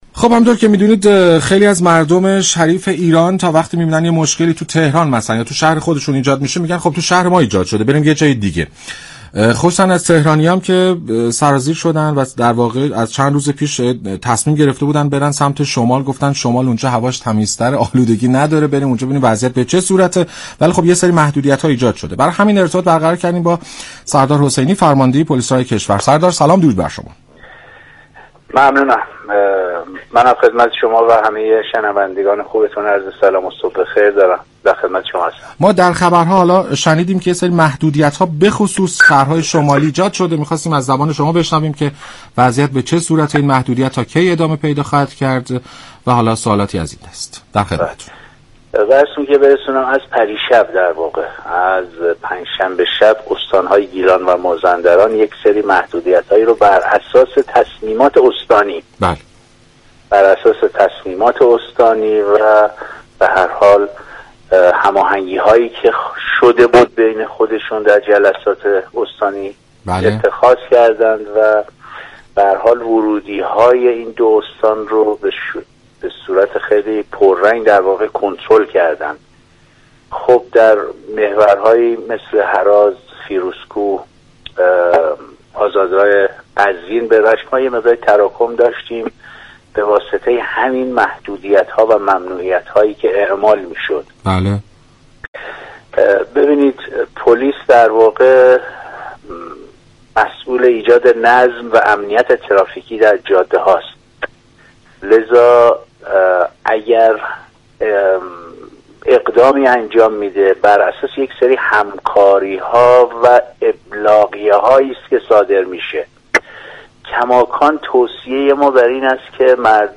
به گزارش شبكه رادیویی ایران، سردار حسینی فرماندهی پلیس راه كشور در برنامه «سلام صبح بخیر» درباره محدودیت های ترافیكی در مسیرهای شمالی كشور گفت: استان های گیلان و مازندران از پنج شنبه شب 15 اسفند محدودیت های ترافیكی بر اساس تصمیمات و هماهنگی صورت گرفته در استانداری اعمال كرده اند و ورودی های مسیر را به شدت كنترل می كنند.